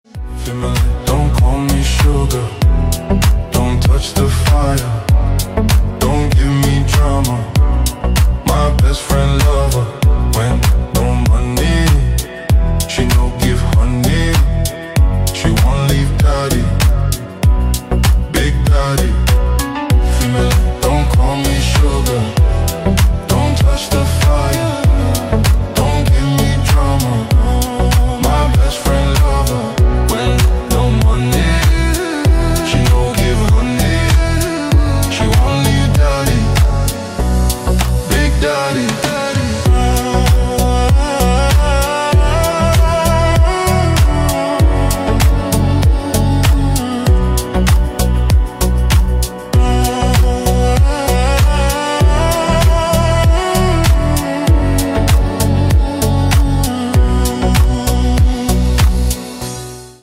Deep House рингтоны